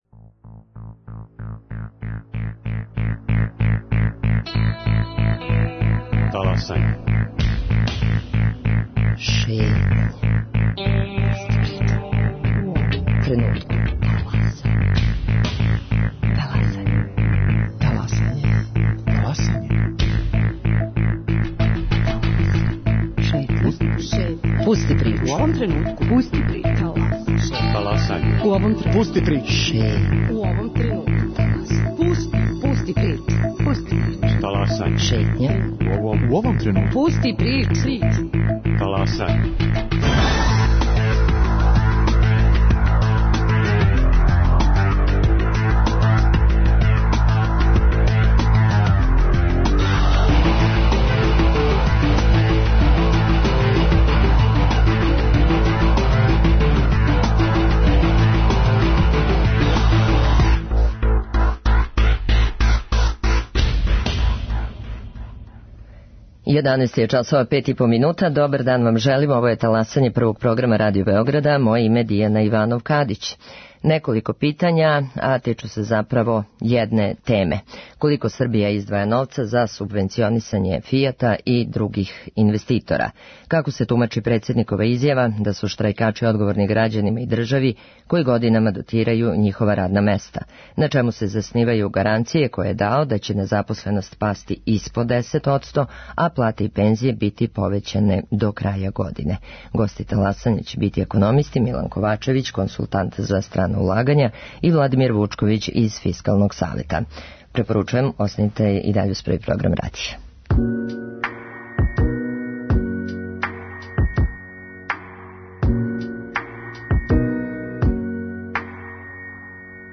Гости Таласања су економисти